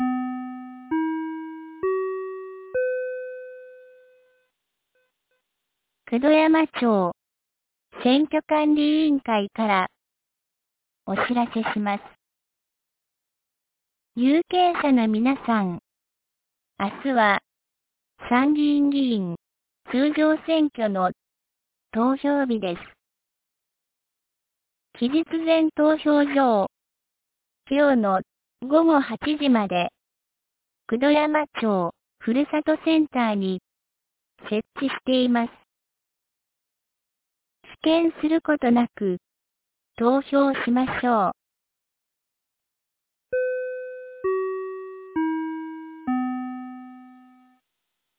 2025年07月19日 12時30分に、九度山町より全地区へ放送がありました。